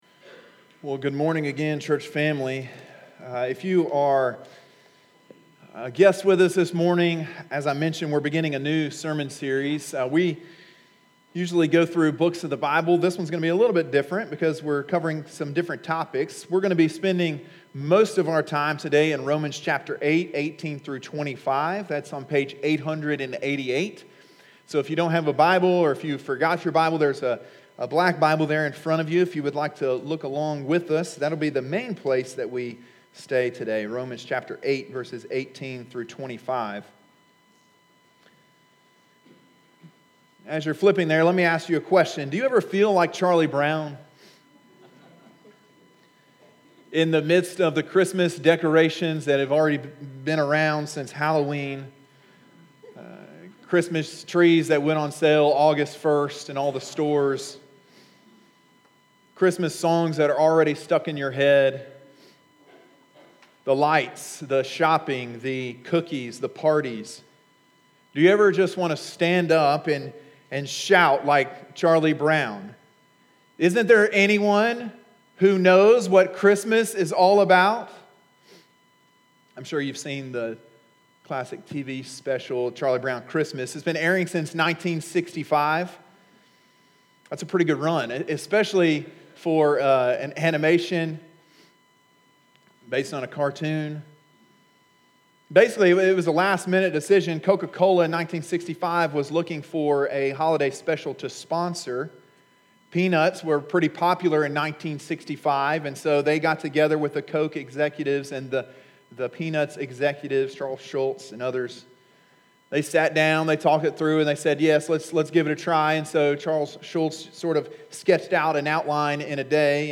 Sermon: “The Gift of Hope” (Romans 8:18-25)